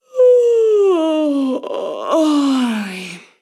Bostezo de una mujer
Voz humana